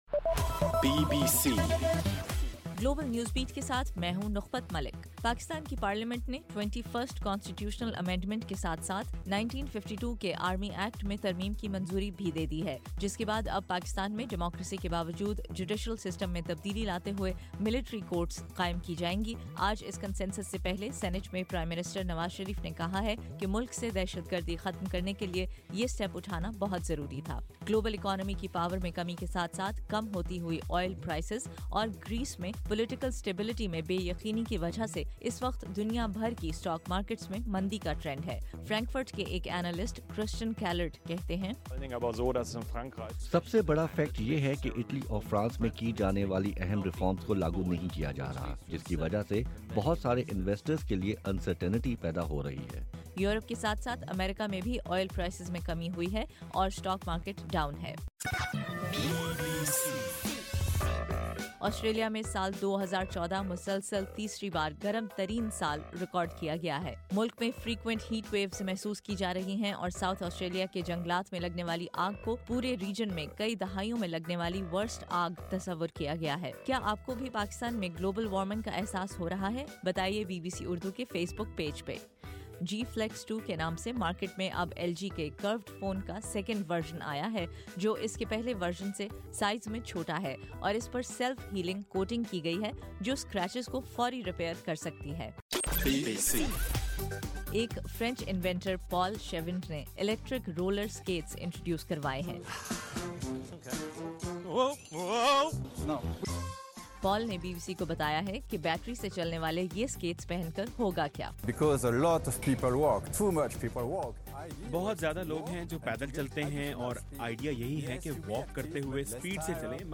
جنوری 6: رات 10 بجے کا گلوبل نیوز بیٹ بُلیٹن